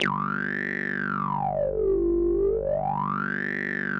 G1_wasp_lead_1.wav